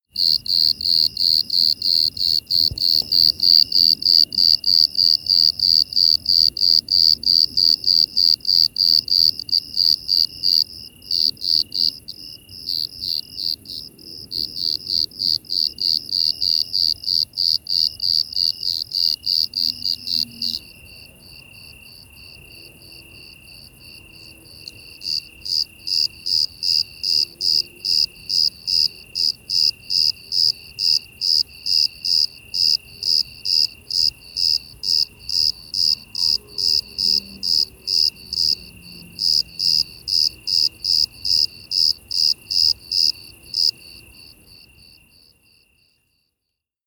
Südliche Grille.mp3